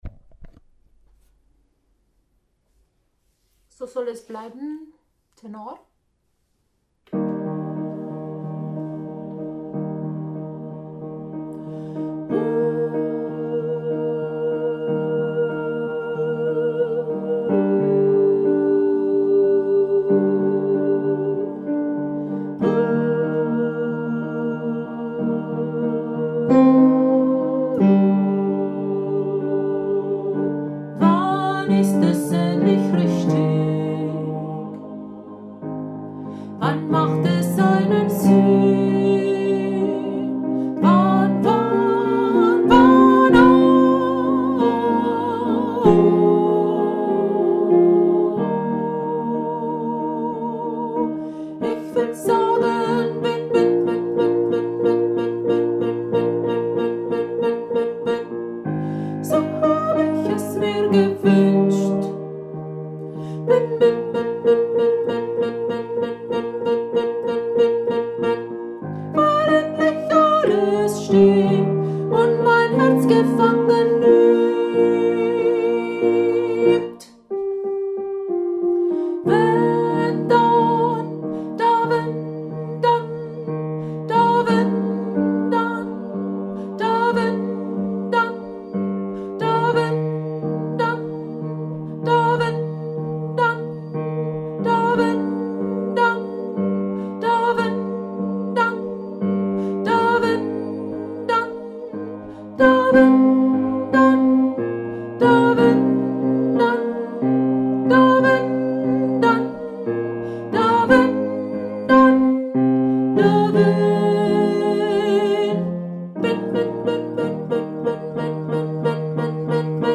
So soll es sein, so kann es bleiben – Tenor